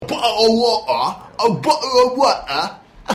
Bottle of water (accent)
bottle-of-water-accent.mp3